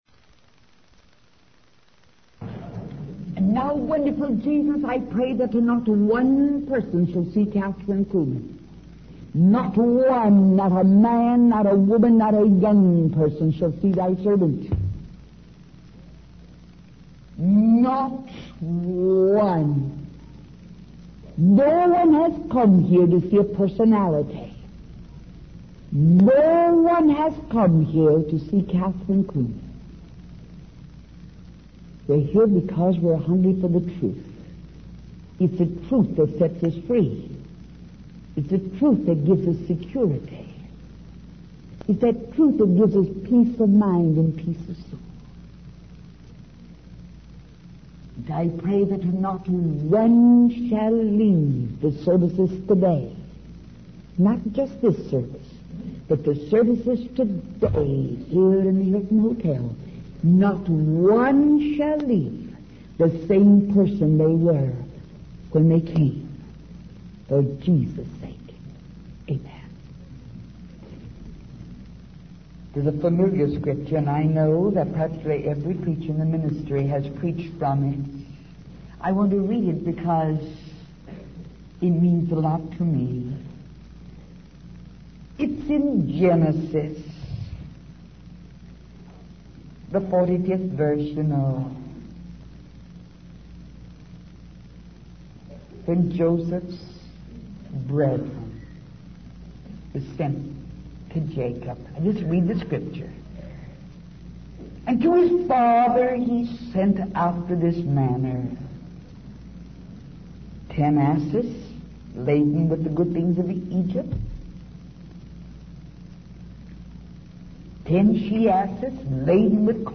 In this sermon, the preacher emphasizes the importance of believing in the word of God.